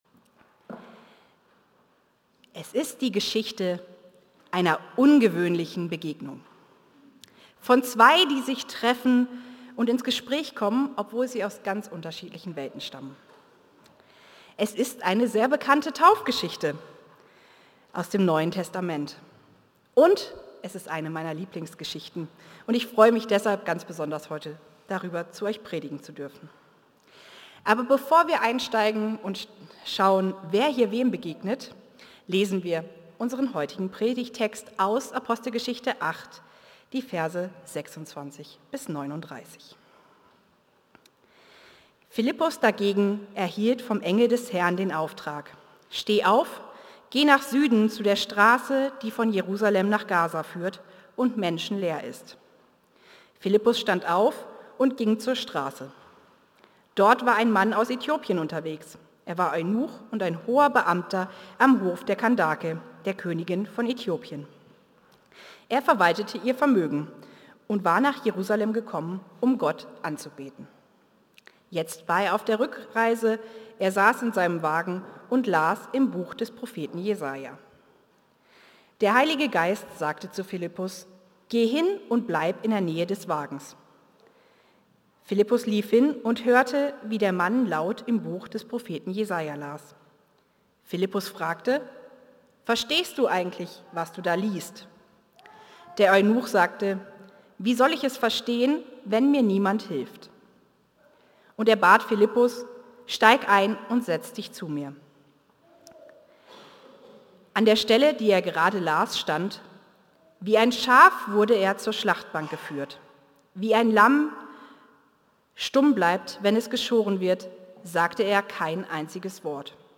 Predigten aus einANDERERGottesdienst